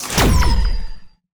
bullet_medic.wav